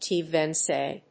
ティー‐エスブイ